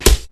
Arrow 1.wav